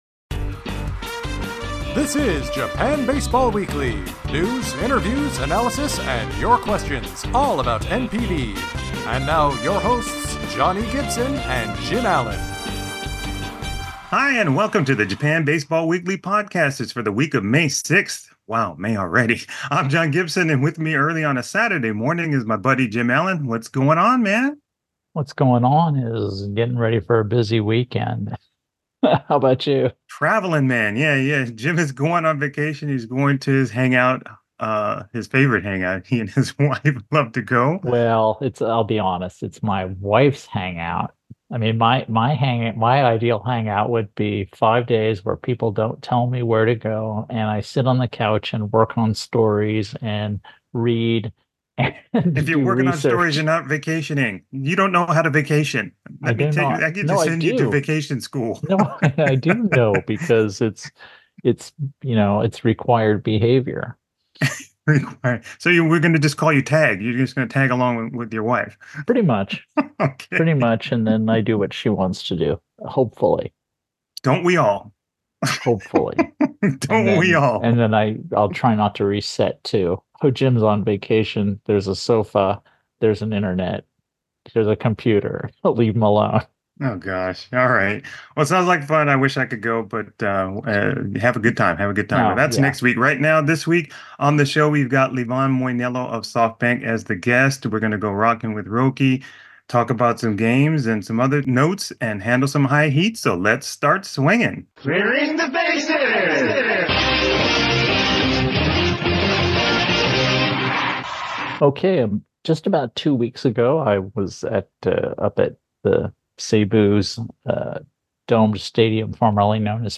Vol. 14.12, Livan Moinelo of SoftBank is the guest, we look at each league, go Rockin with Roki, take a crack at who will be named top performers of the month, hit a note about a new stadium and handle some HighHeat.